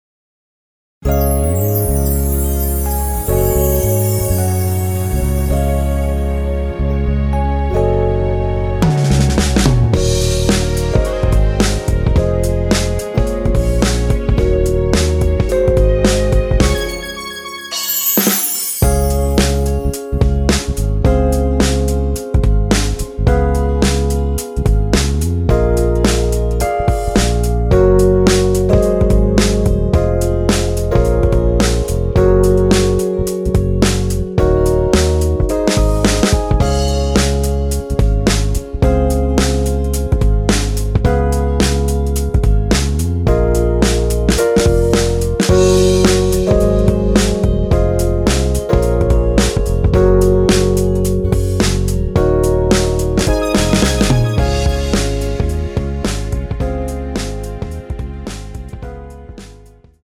원키에서(-4)내린 MR입니다.
Db
앞부분30초, 뒷부분30초씩 편집해서 올려 드리고 있습니다.